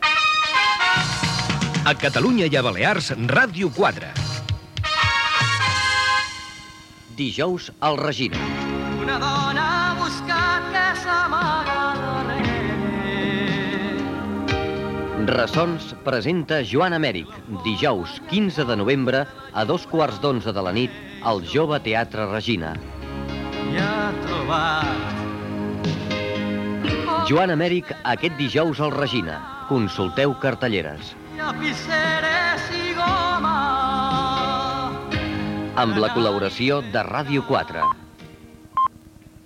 Indicatiu de l'emissora (a Catalunya i Balerars), publicitat de Ressons al Jove Teatre Regina